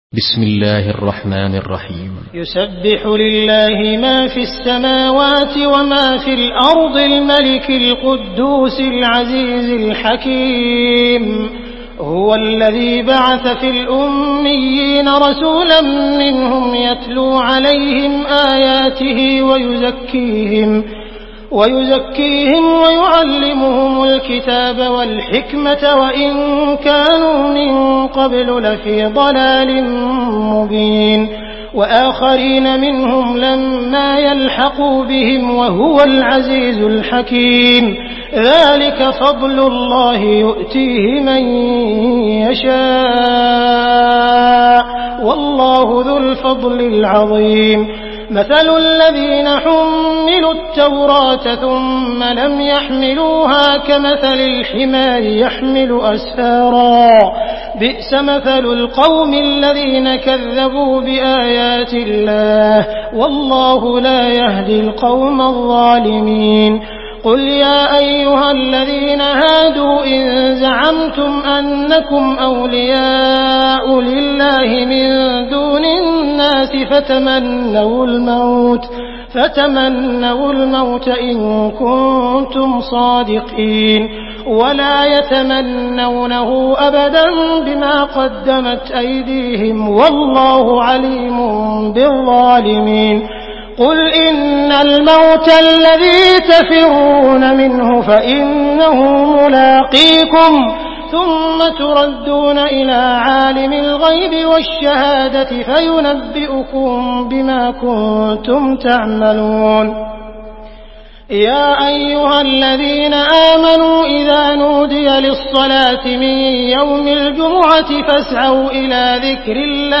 سورة الجمعة MP3 بصوت عبد الرحمن السديس برواية حفص
مرتل